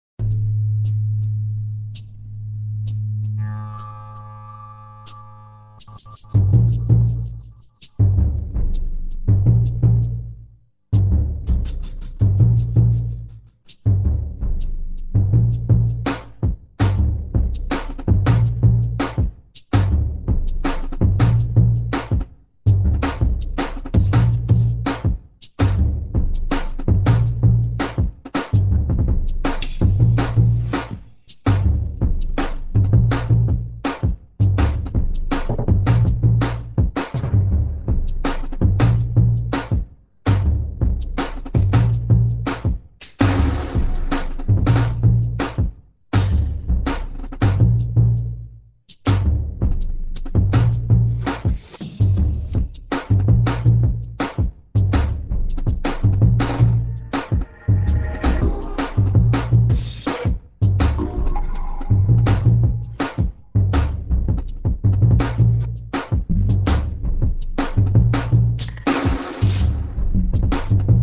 Dub, Downbeat und Drum&Bass